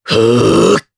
Riheet-Vox_Casting3_jp.wav